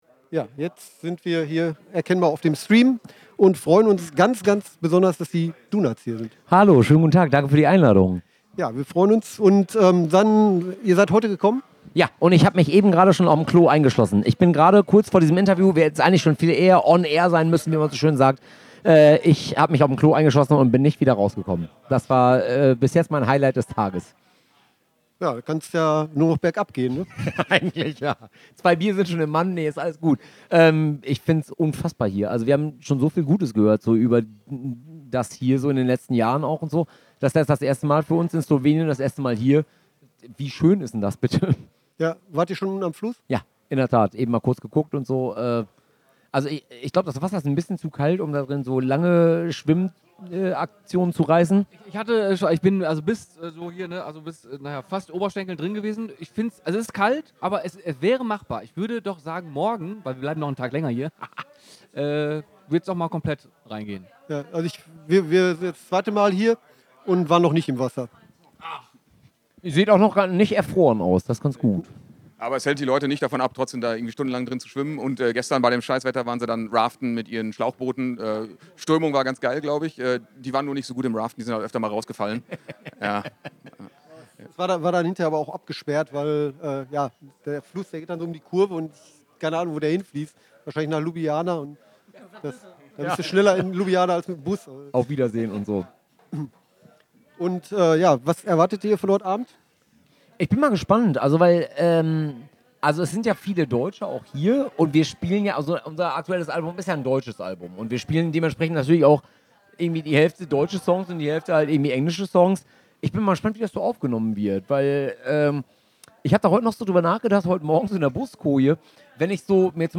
interview-mit-den-donots-beim-punk-rock-holiday-1-6-mmp.mp3